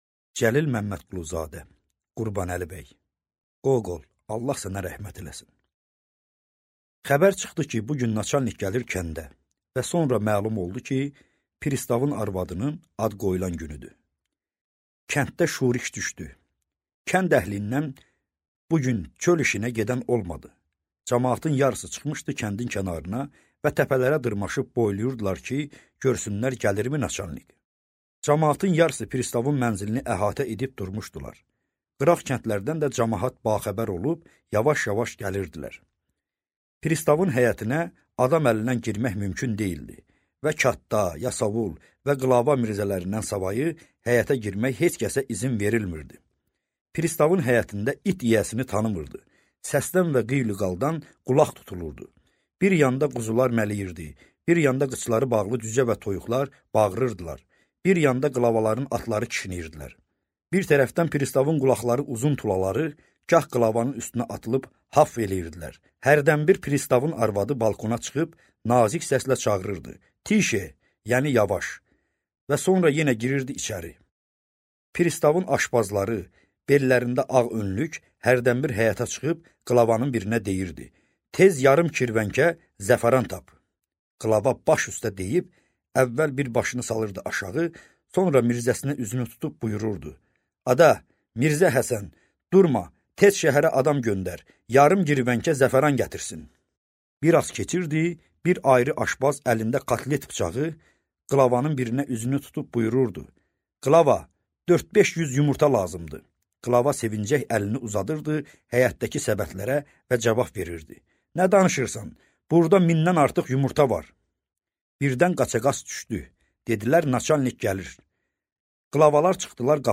Аудиокнига Qurbanəli bəy | Библиотека аудиокниг